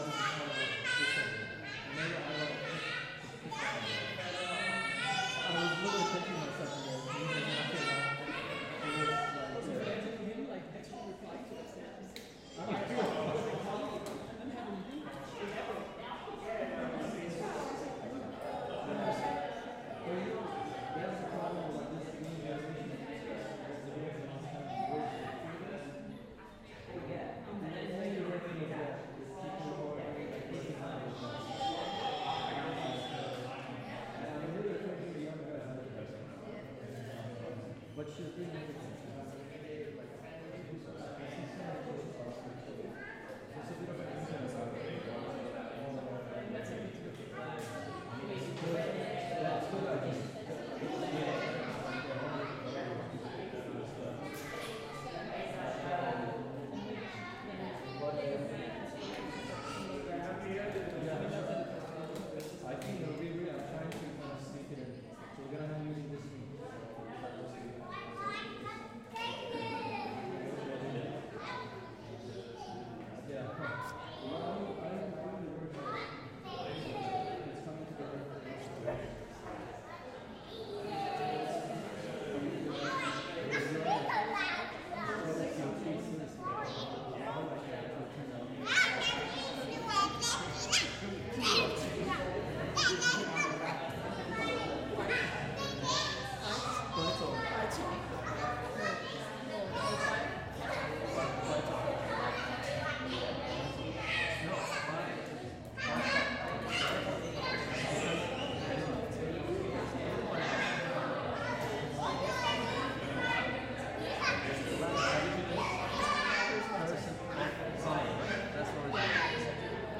Download audio from performance
The sculpture was constructed within the duration of the performance.